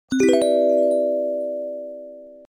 Market.wav